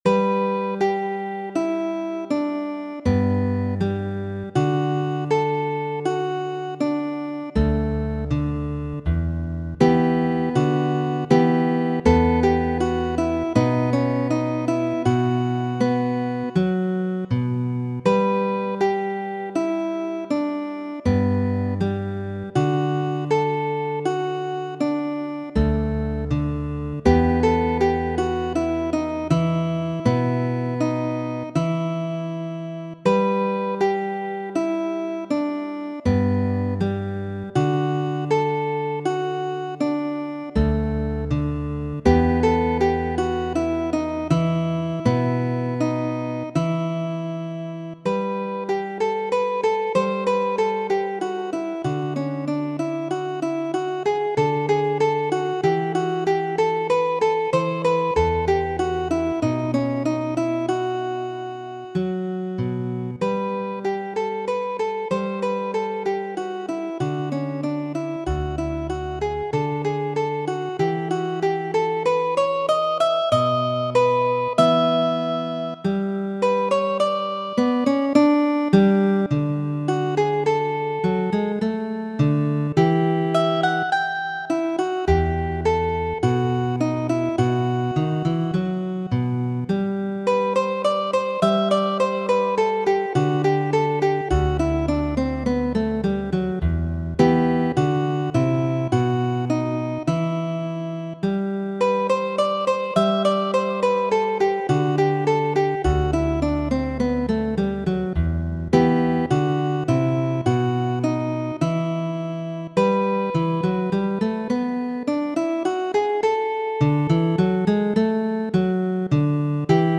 Kajero 48 ª ~ Kajeroj Muziko: Gardu tiujn bovinoj por mi , variaĵoj komponitaj de Luys de Narváez en la 16-a jarcento, kaj luditaj de mi ĉe via komputilo.